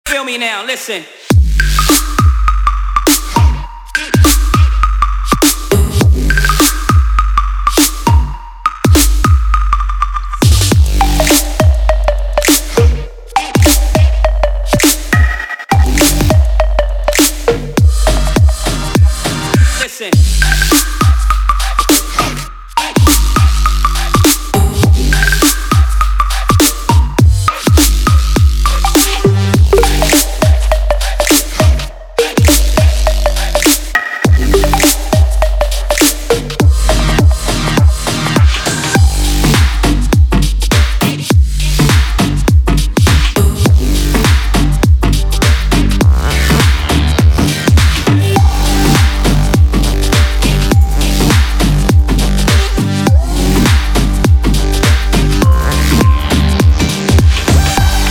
• Качество: 320, Stereo
громкие
качающие
Bass
glitch hop